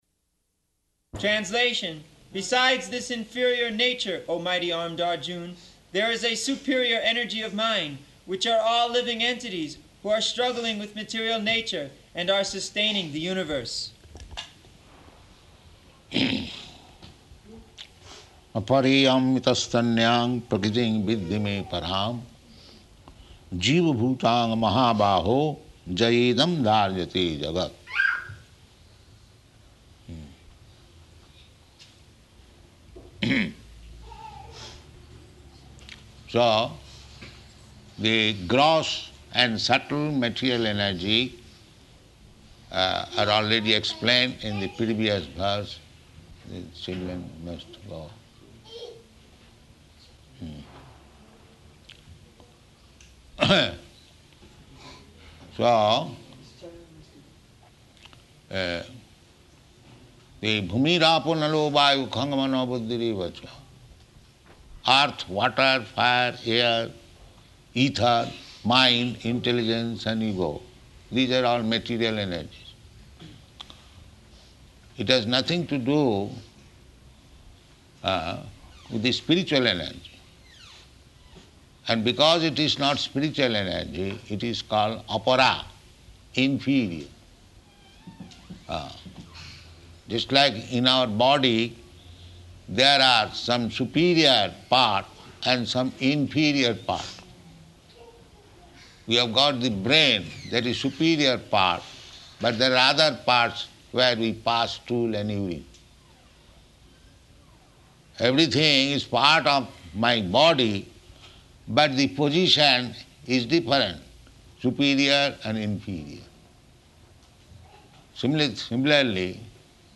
Location: Nairobi
[child making noises] [aside:] The children must go.